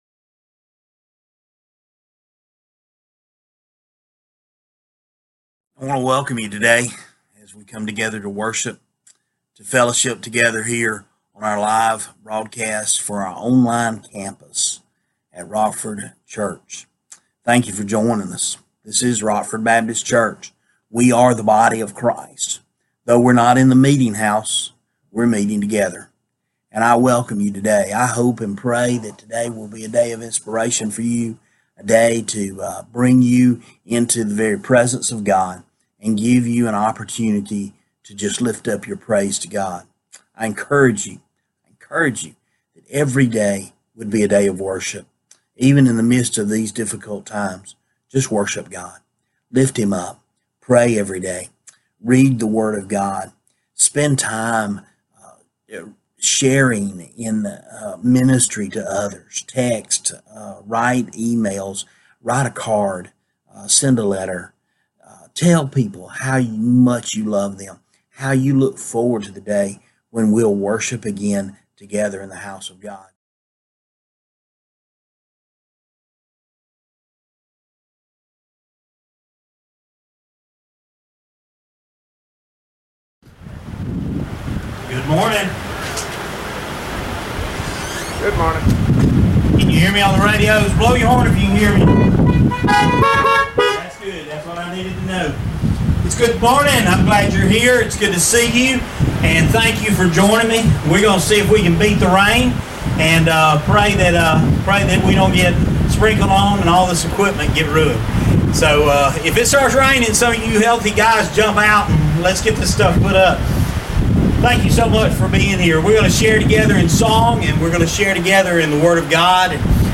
This is the audio of the entire service:
Service Type: Sunday Morning